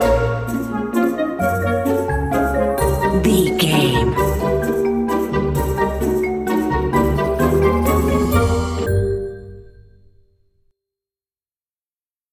Uplifting
Ionian/Major
D
Slow
flute
oboe
strings
orchestra
cello
double bass
percussion
violin
sleigh bells
silly
comical
cheerful
perky
Light hearted
quirky